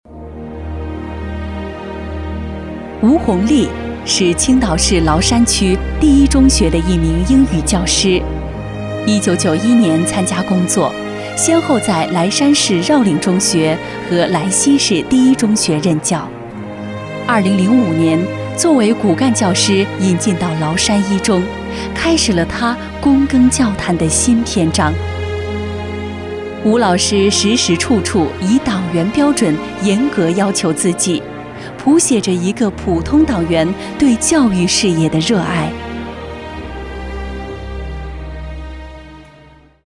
标签： 舒缓
配音风格： 成熟 舒缓 大气 沉稳 稳重 亲切